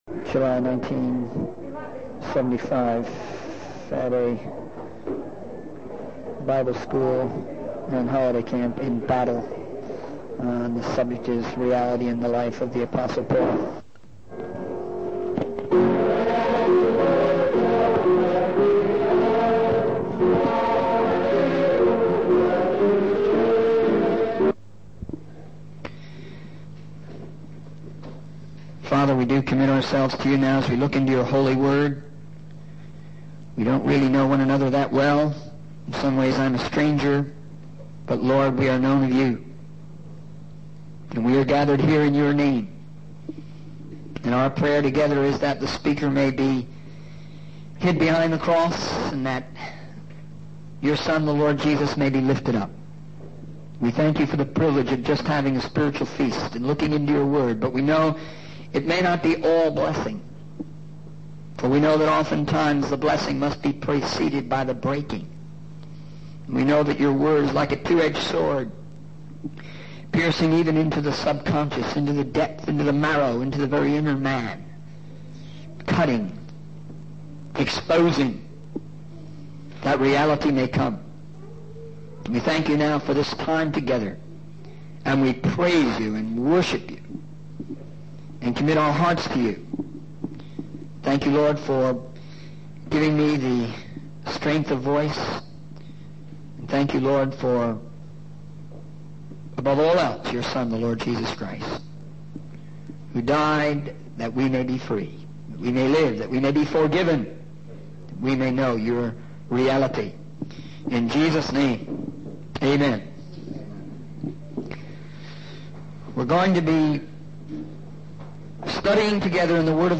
In this sermon, the speaker reflects on the criticisms and challenges faced in their ministry. They emphasize the importance of not letting small mistakes or wasted words hinder the message of God's Word. The speaker reads from 1 Corinthians 4:9, highlighting the apostles' role as fools for Christ's sake, yet wise in Him.